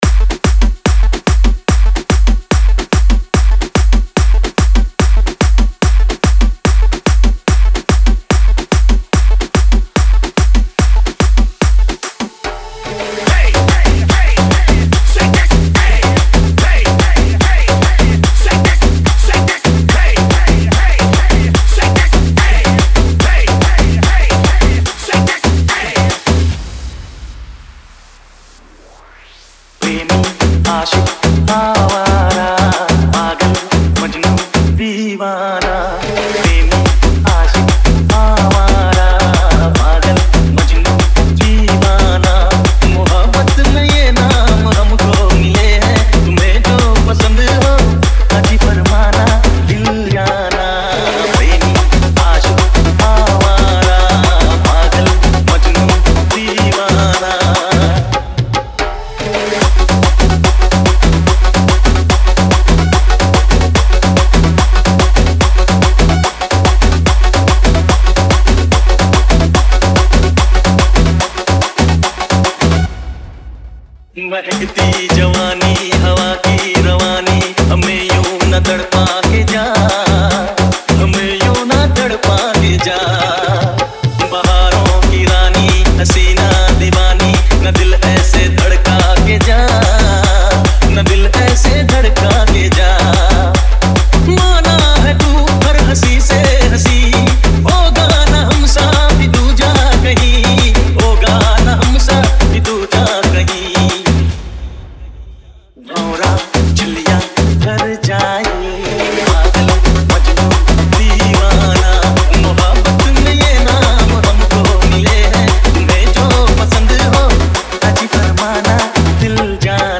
Circuit Mix